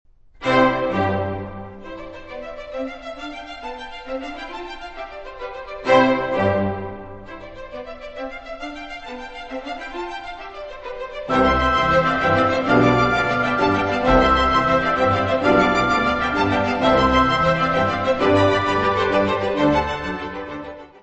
Music Category/Genre:  Classical Music
Allegro maestoso.